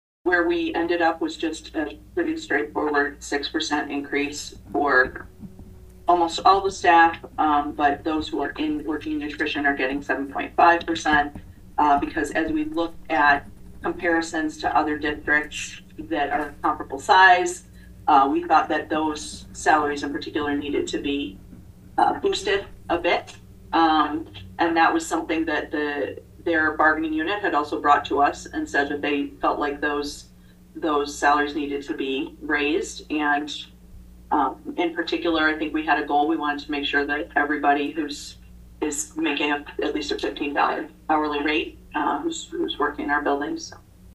(Atlantic) The Atlantic School Board approved the Classified Support Staff Union Contract for 2024-25 at their meeting Wednesday evening.
Board Member Laura McLean explained the salary increases….